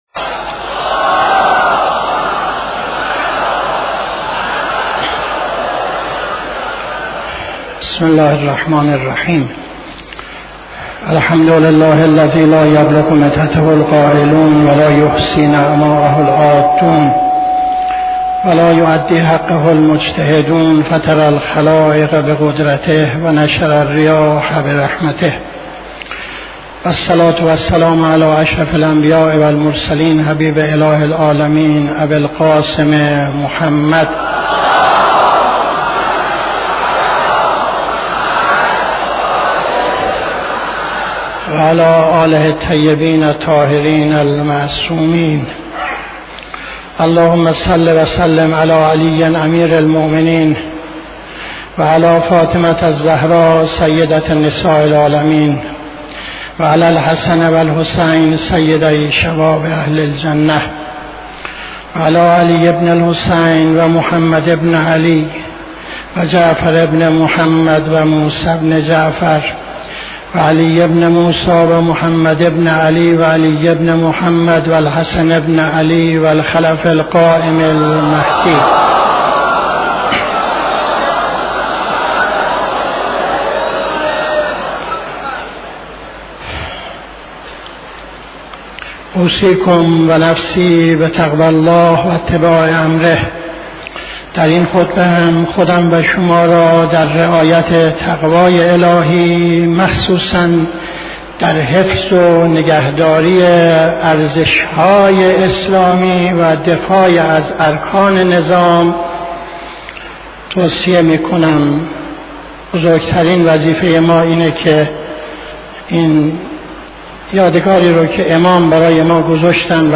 خطبه دوم نماز جمعه 05-06-78